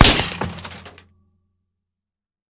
3098b9f051 Divergent / mods / JSRS Sound Mod / gamedata / sounds / material / bullet / collide / metal02gr.ogg 60 KiB (Stored with Git LFS) Raw History
metal02gr.ogg